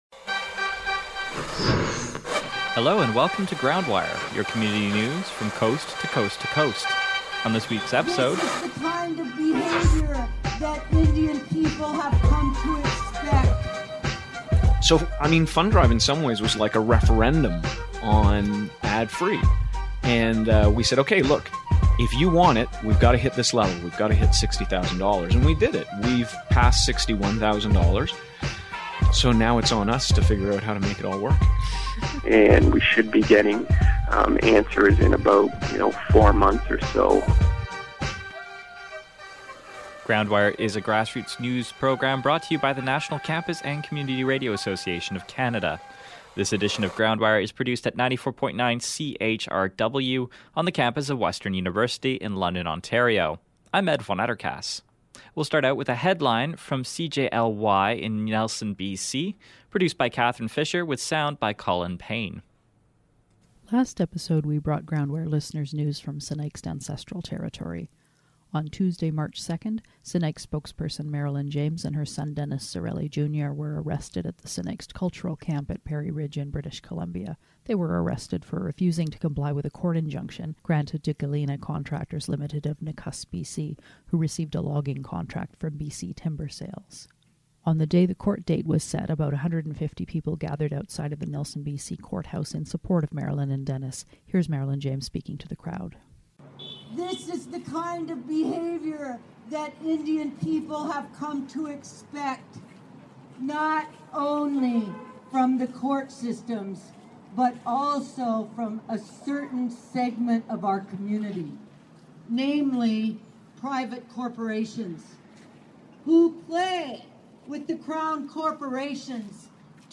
GroundWire Community Radio News March 10-24th